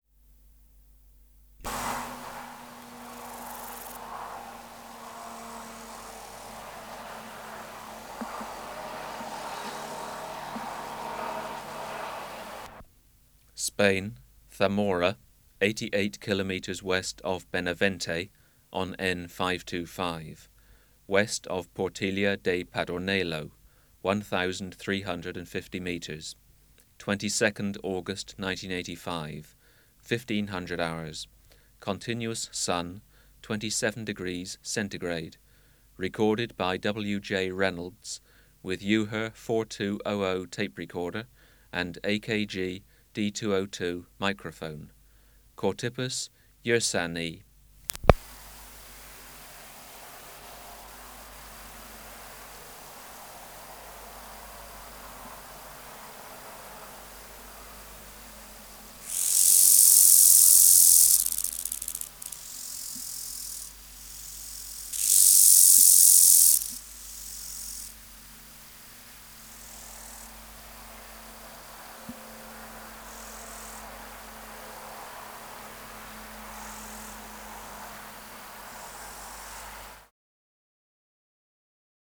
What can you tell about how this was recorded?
Recording Location: Europe: Spain: Zamora, 88kn W of Benavente, W of Portilla de Padornelo Air Movement: Breeze Substrate/Cage: On grass Microphone & Power Supply: AKG D202 (-32dB at 50Hz) Distance from Subject (cm): 10 Recorder: Uher 4200